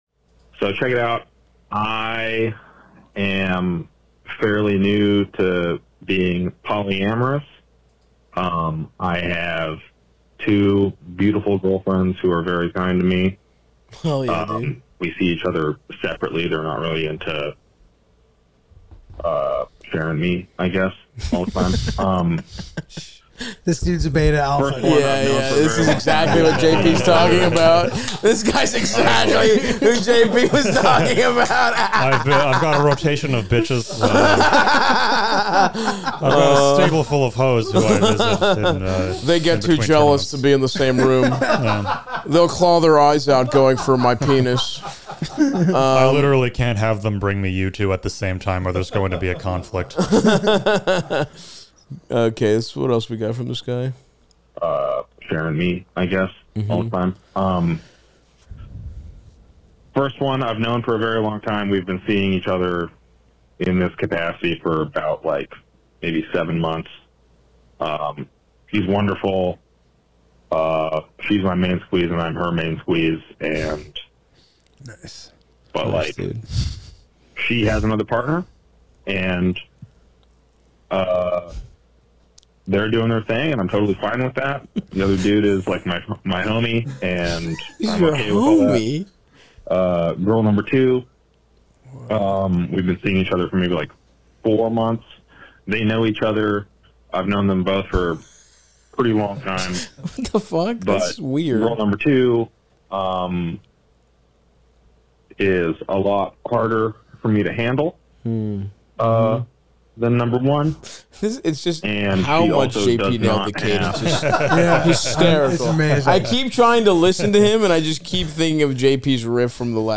Patreon preview.